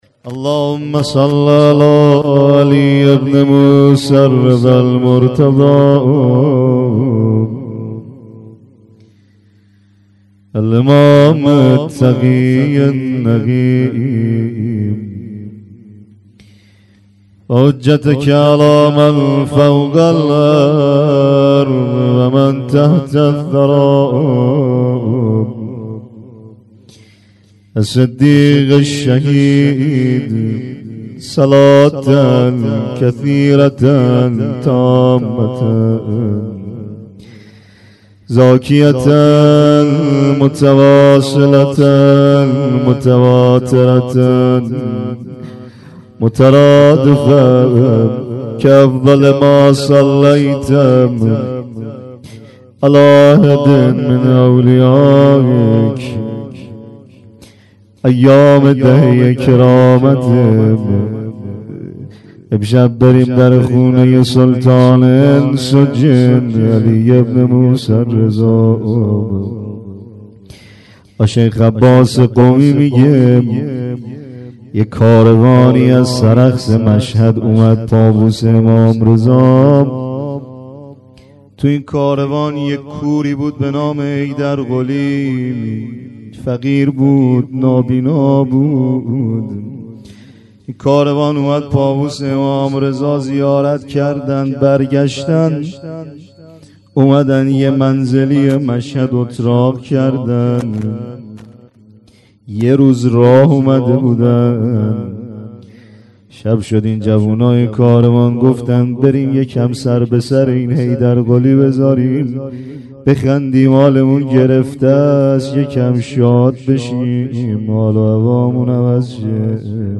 monajat 93.06.14.mp3